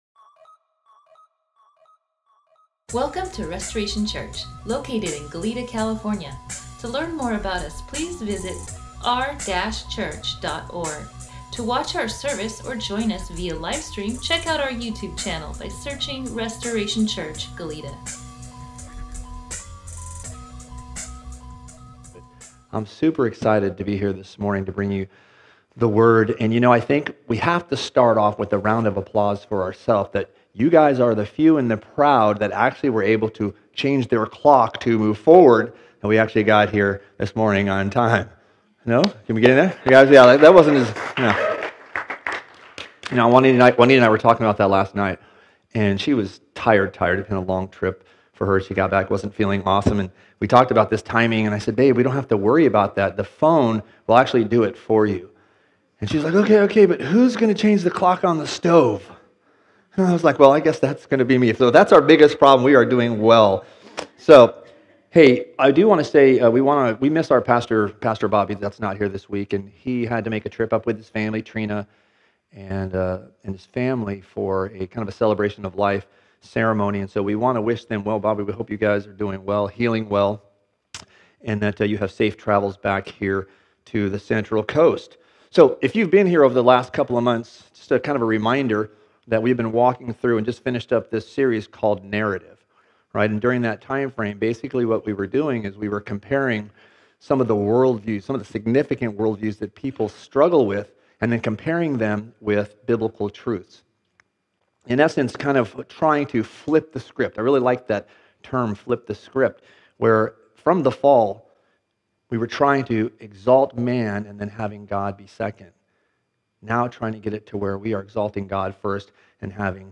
Stand Alone Sermon